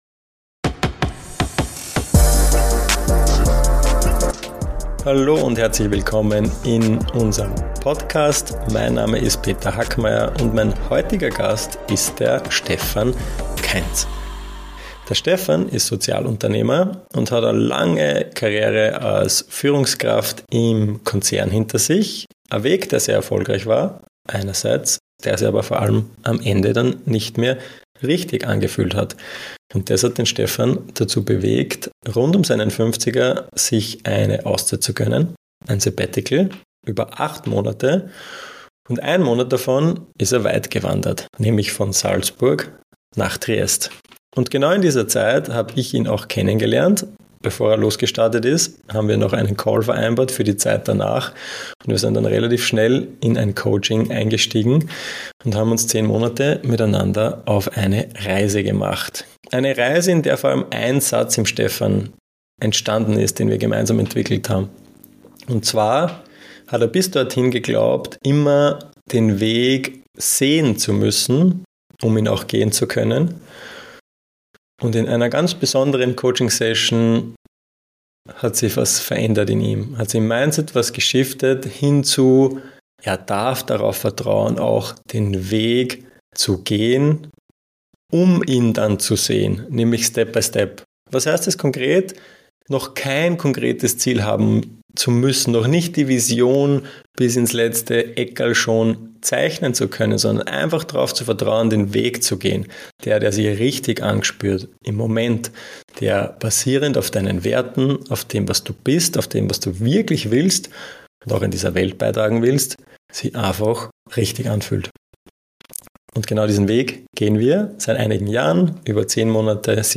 Unser Podcast ist ein echt schönes Gespräch über Erfolg mit Sinn, High Performance mit Familie, Impact mit Leichtigkeit.